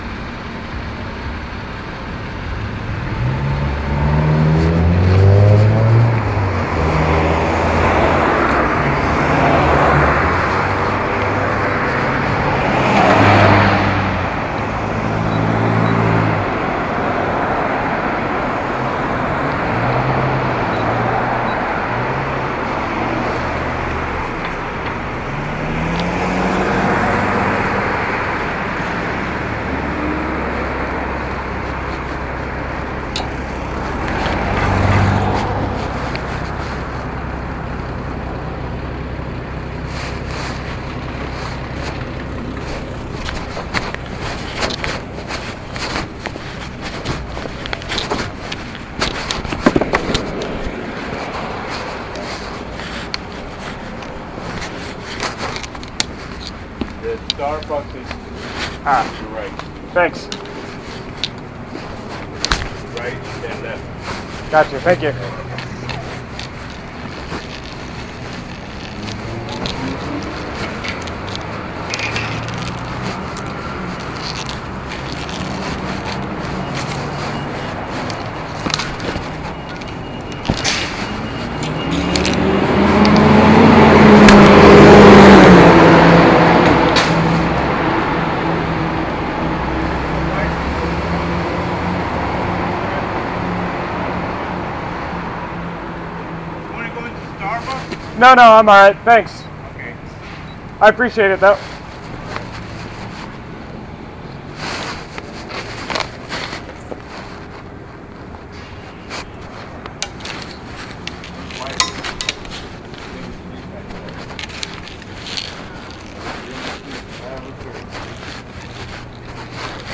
Sounds as I cross Fulton street and have to struggle to avoid being sent into a Starbucks. The occasional beeps are from my camera.